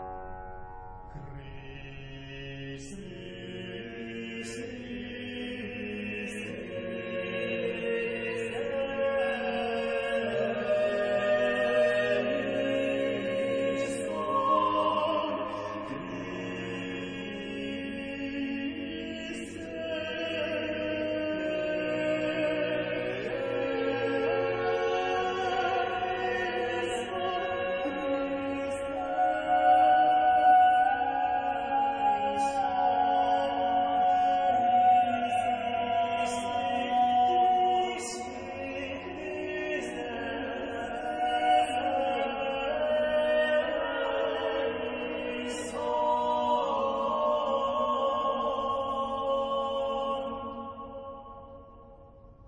是在凡爾賽宮的皇家禮拜堂的管風琴錄製的。
凡爾賽宮皇家禮拜堂的大管風琴上，聲音華麗。
這張演出由巴黎聖母院的大管風琴座Olivier Latry擔綱。
整個作品，由一曲管風琴一曲人聲合唱，交織而成。
每首曲子都不長，帶著崇高神聖的即興創作，